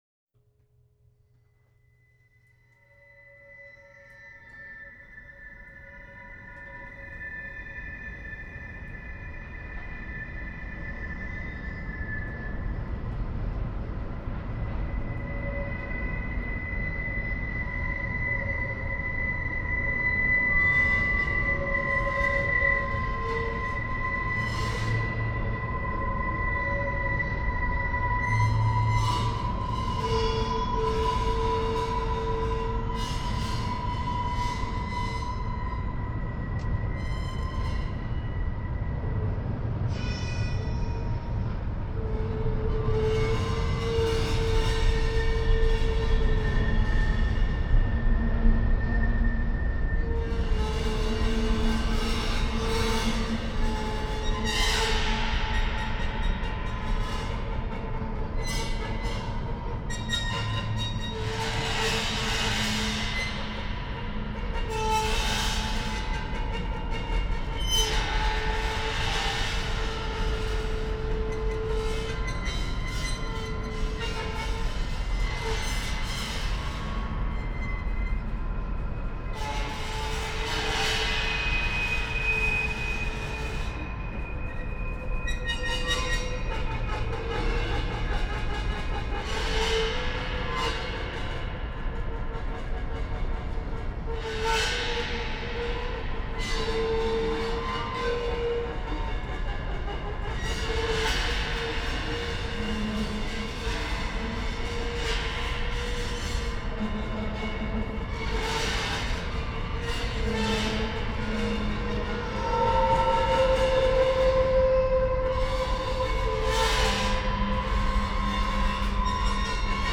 Featuring field recordings of Winnipeg trains
Recorded in Toronto in August, 2021